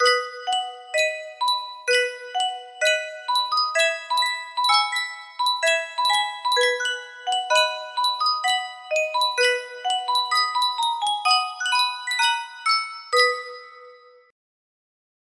Sankyo Music Box - Praise Him, Praise Him 9Q music box melody
Full range 60